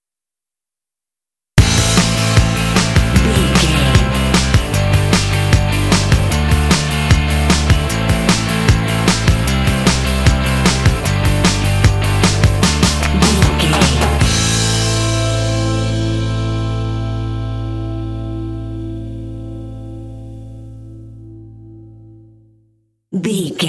Fast paced
Aeolian/Minor
fun
bouncy
groovy
drums
electric guitar
bass guitar